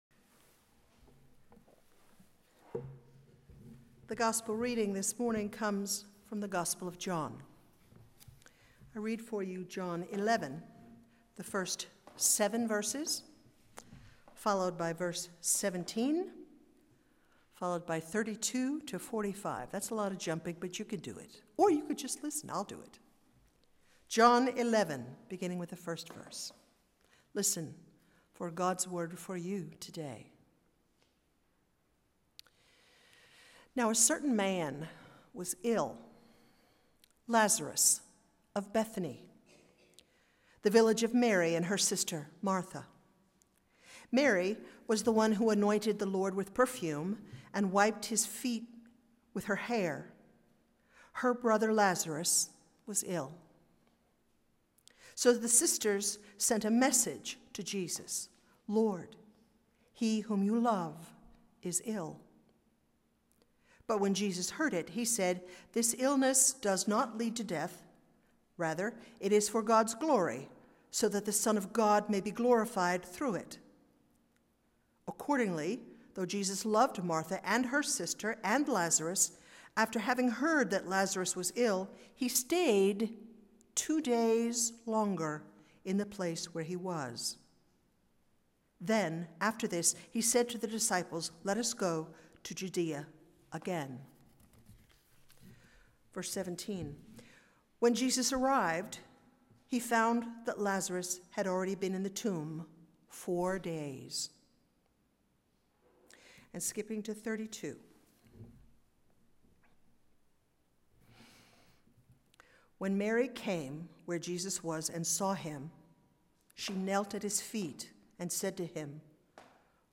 Sermon 11-1-15